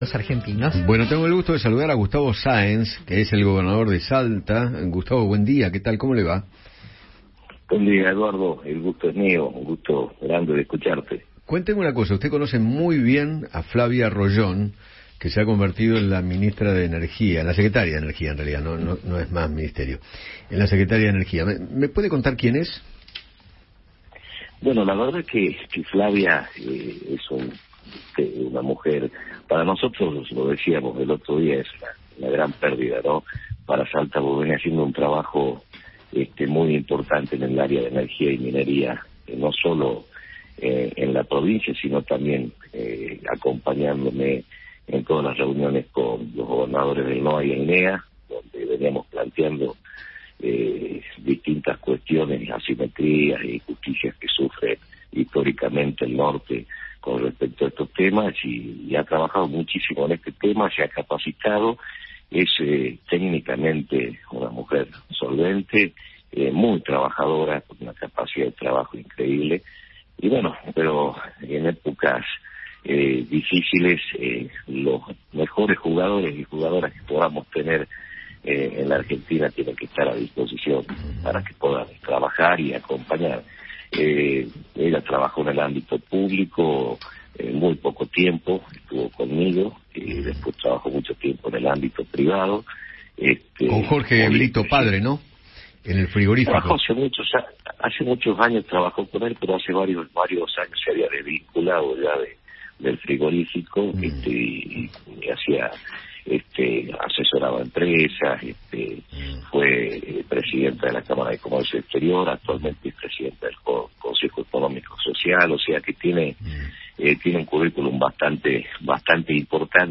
Gustavo Sáenz, gobernador de Salta, habló con Eduardo Feinmann sobre la incorporación de Flavia Royón al equipo de Sergio Massa, lo que implicó la salida de su Gabinete.